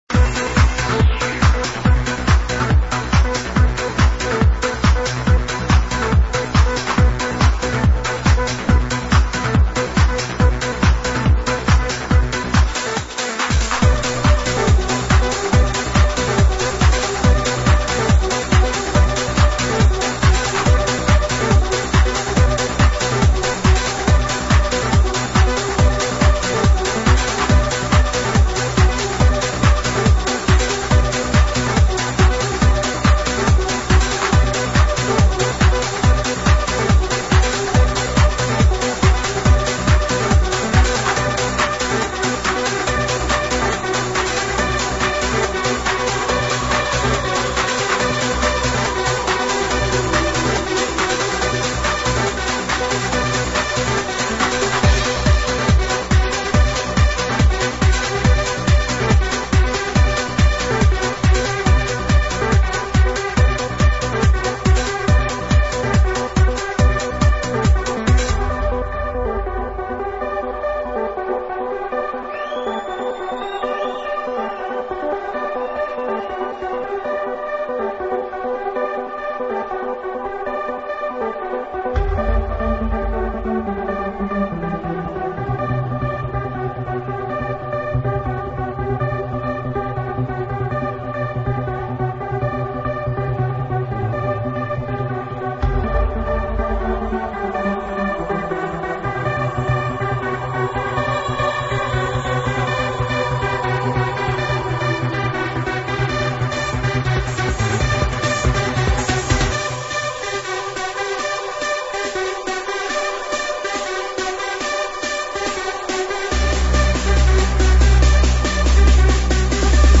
друзья,по-настоящему хороший trance.